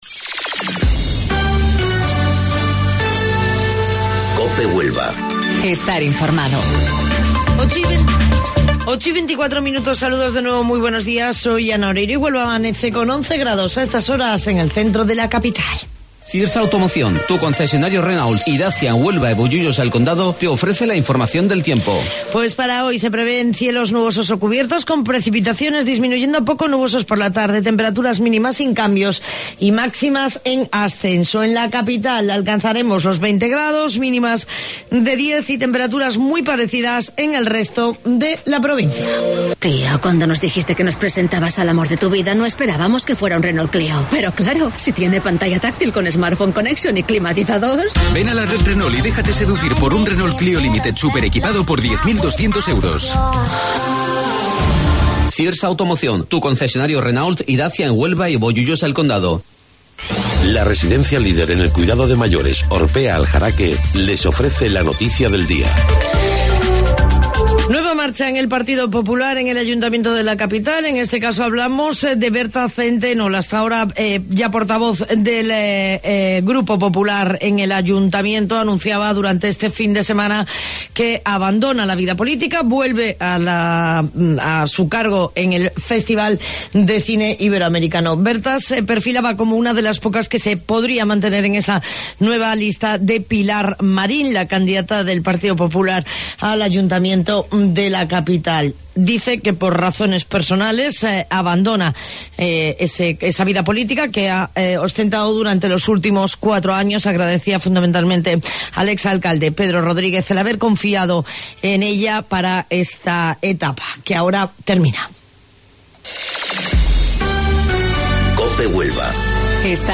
AUDIO: Informativo Local 08:25 del 8 de Abril